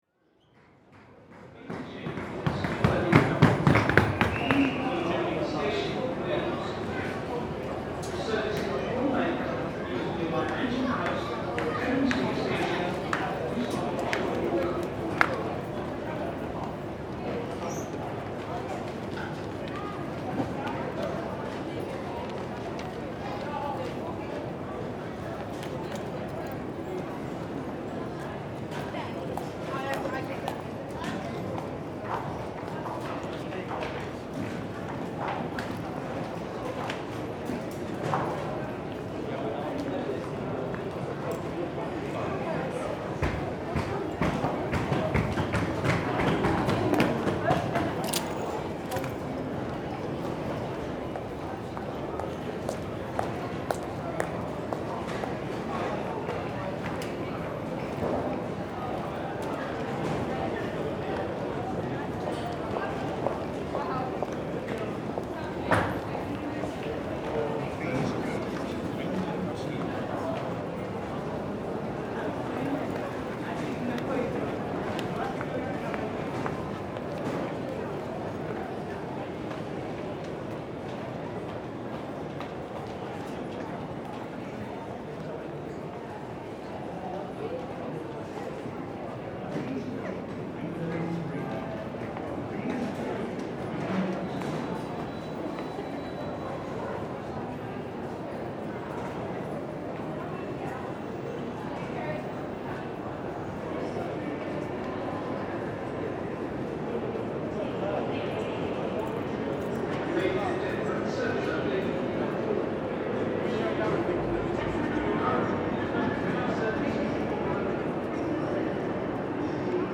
Liverpool Street Station
8 locations in london, 4 urban spaces, 4 unspoilt nature…
in contrast, areas such as notting hill gate and st. pauls are filled with urban drone and the bustle of pedestrians, although quieter than you’d imagine. oxford circus and liverpool street station however are exactly what you would expect – busy, noisy, smelly, managing to be both colourful and grey simultaneously.
8LondonLocations_06_LiverpoolStreetStation.mp3